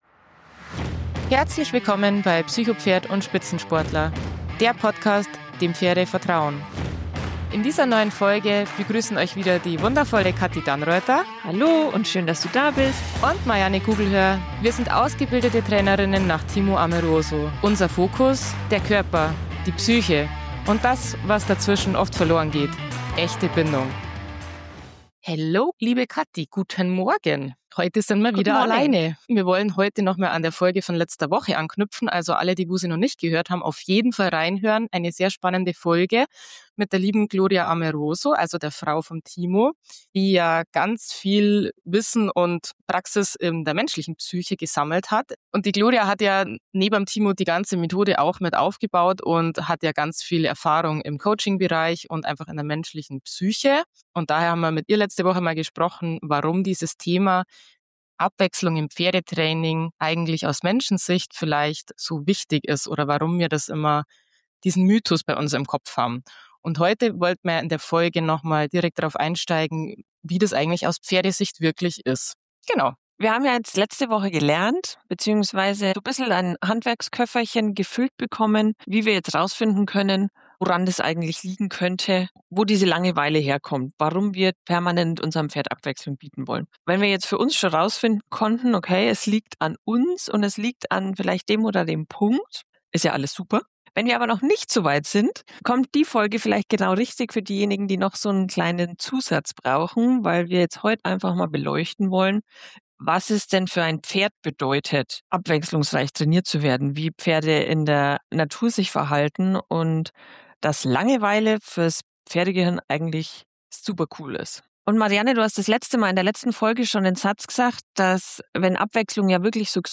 Freut euch auf ein tiefgehendes Gespräch, praxisnahe Beispiele und Impulse, wie ihr euer Pferd auf biologisch sinnvolle Weise begleiten könnt.